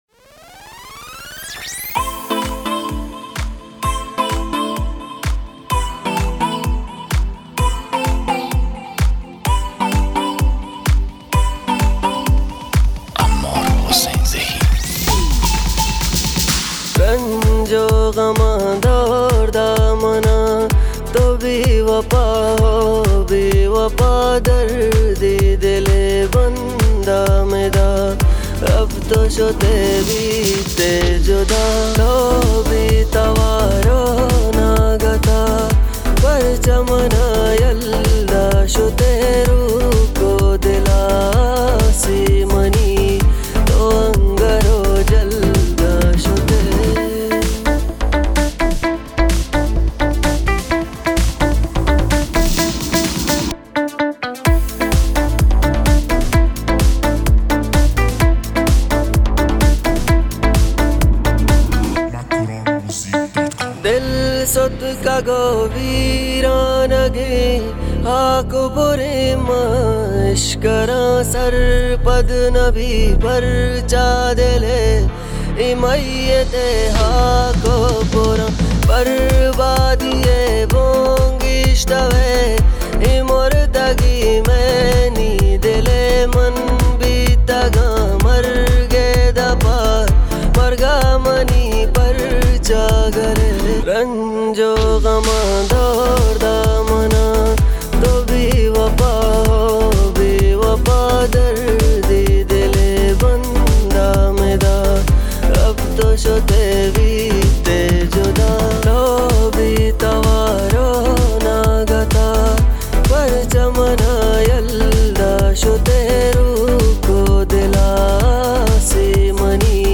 ترانه آرامش بخش و دلنشین
بلوچی